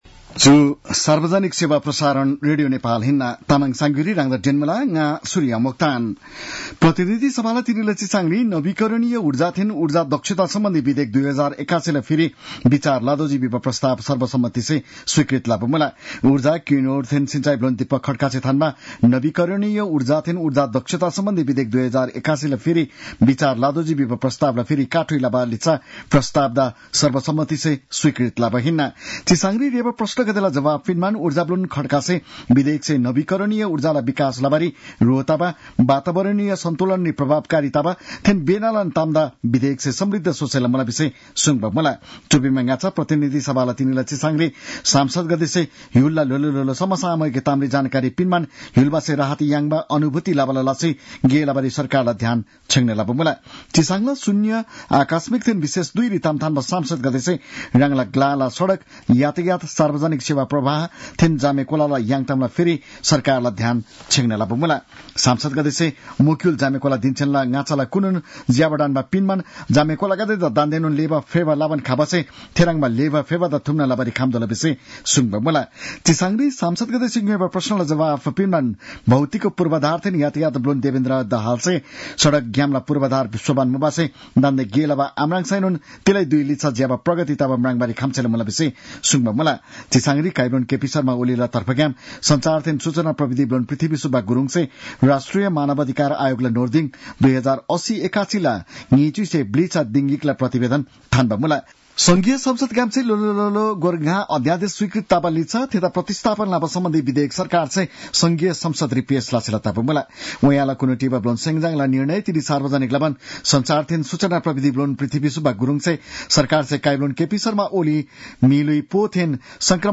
तामाङ भाषाको समाचार : २४ फागुन , २०८१